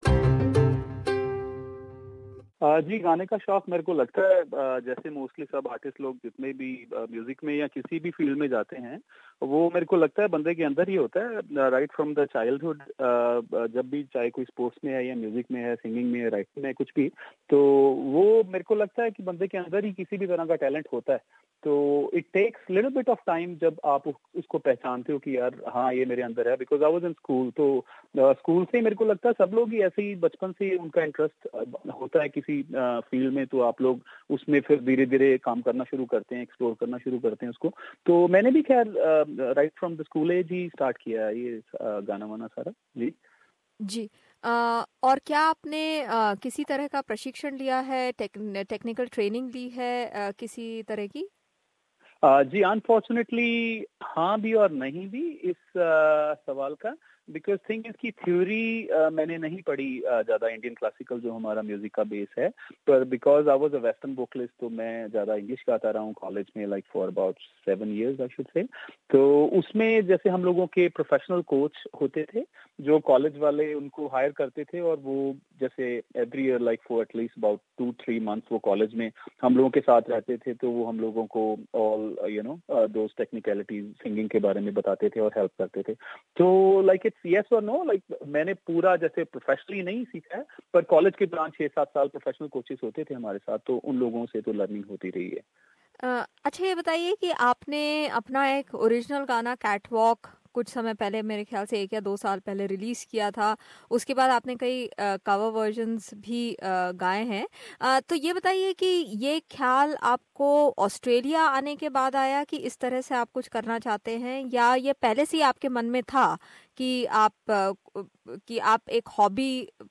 Tune in for this free flowing chat with the Canberra based Indian-Australian singer.